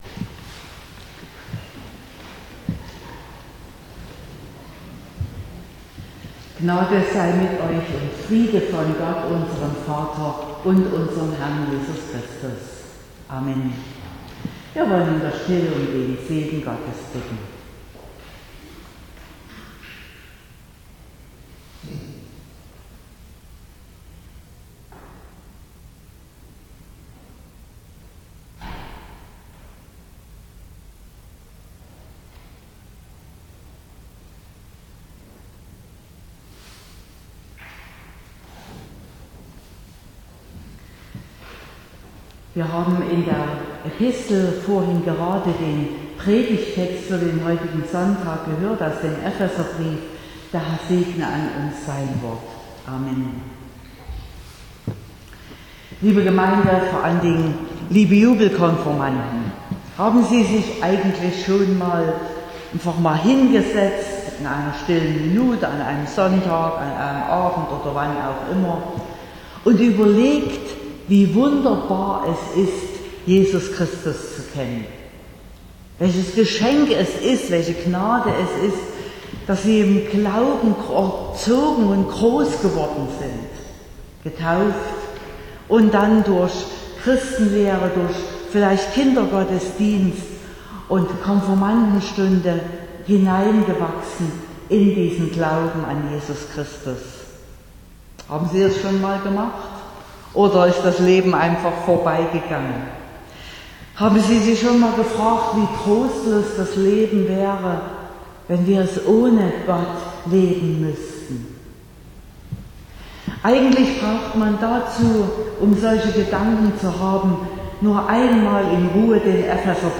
Predigt (Audio): 2024-06-09_Mit_Jesus_Frieden_im_Herzen.mp3 (26,5 MB)